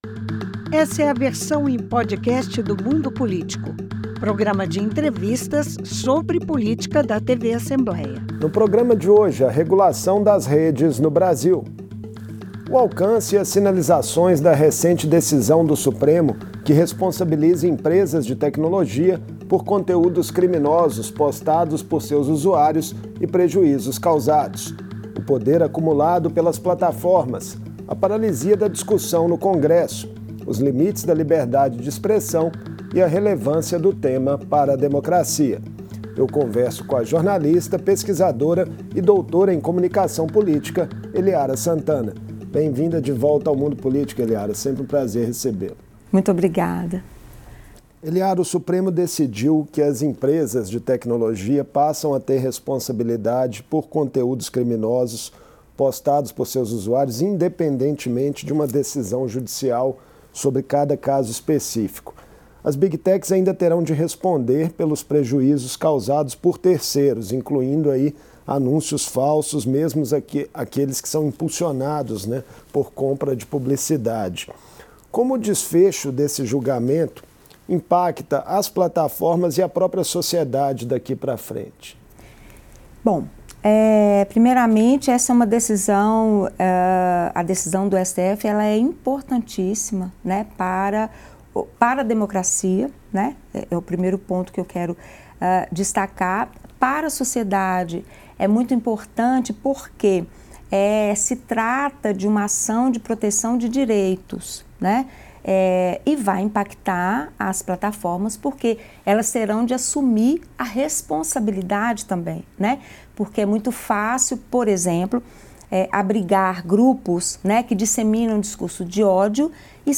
Os ministros do Supremo Tribunal Federal decidiram que as plataformas são responsáveis pelo conteúdo exibido nas redes sociais. Em entrevista